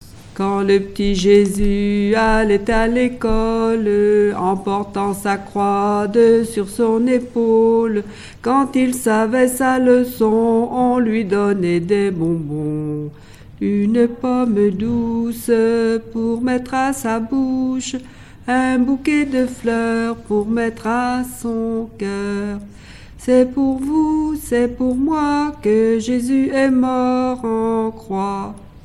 Mémoires et Patrimoines vivants - RaddO est une base de données d'archives iconographiques et sonores.
répertoire de chansons traditionnelles
Pièce musicale inédite